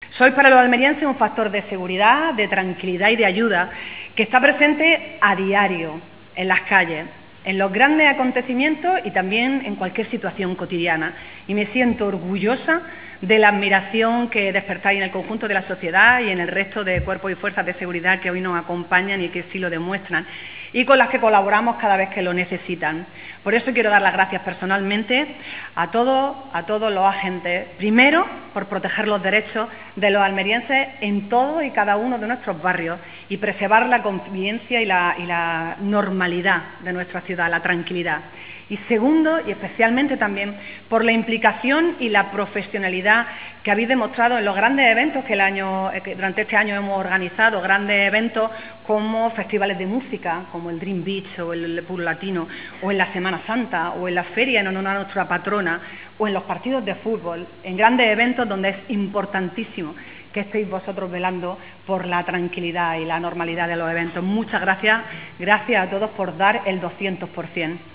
La alcaldesa de Almería, María del Mar Vázquez, ha ensalzado la “respuesta profesional, serena y respetuosa” que los miembros de la Policía Local han ofrecido en los servicios realizados a lo largo de este año “lleno de retos compartidos y superados”. Así lo ha trasladado en la celebración de los actos conmemorativos del día de San Esteban, Patrón de la Policía Local de Almería, que ha presidido en las instalaciones de la Jefatura en la capital.
TOTAL-ALCALDESA-PATRON-POLICIA-LOCAL.wav